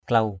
/klau/ (d.) ba = trois. three. sa dua klau s% d&% k*~@ một hai ba. one two three. klau urang k*~@ ur/ ba người. three peoples.
klau.mp3